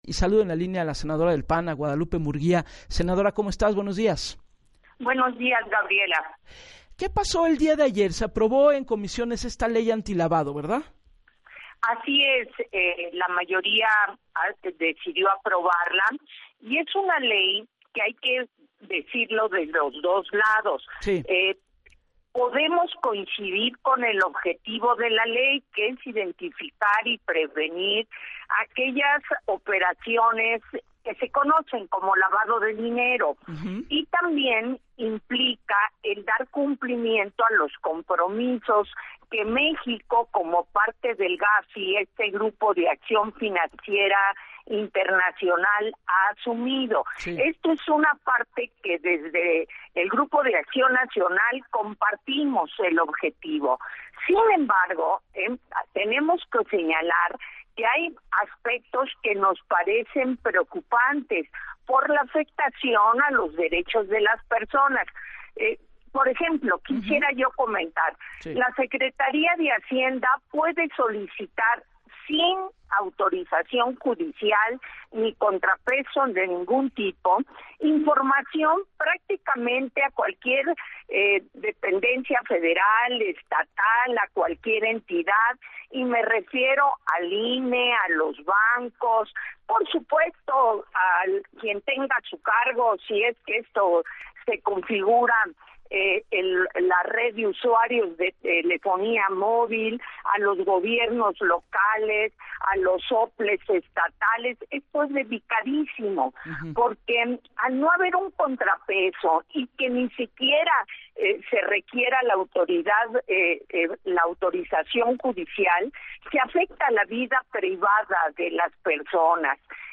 La “ley antilavado” aprobada ayer en Comisiones en el Senado, “es una ley en la que podemos coincidir con el objetivo, que es prevenir el lavado de dinero y dar cumplimiento a los compromisos de comercio internacional de México, sin embargo, ”hay aspectos que nos parecen preocupantes por la afectación a los derechos de las personas”, señaló la senadora del Partido Acción Nacional, Guadalupe Murguía en el espacio de “Así las Cosas” con Gabriela Warkentin.